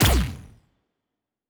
Weapon 03 Shoot 3.wav